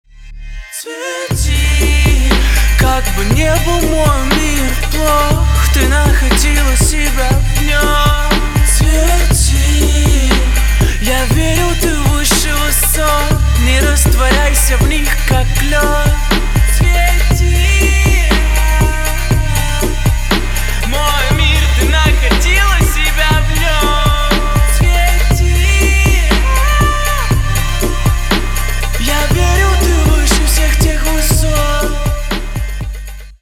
• Качество: 320, Stereo
восточные мотивы
красивый мужской голос
русский рэп
спокойные